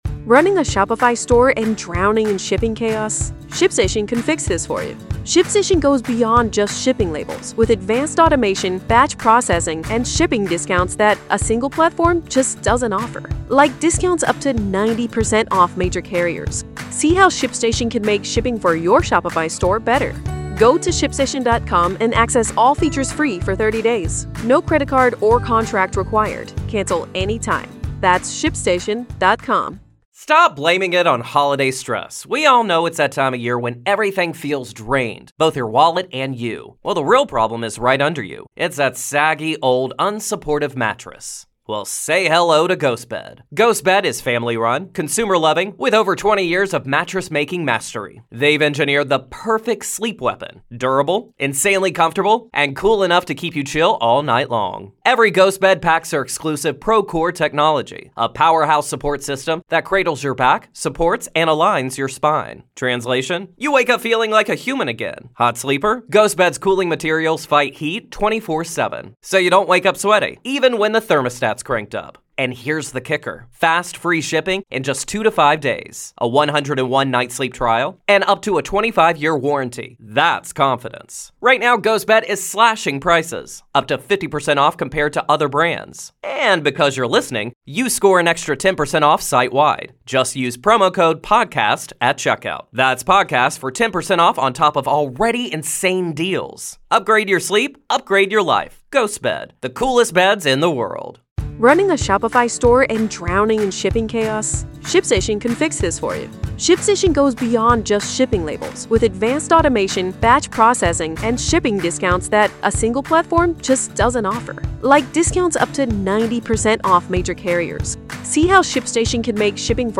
Hollywood Forever | Interview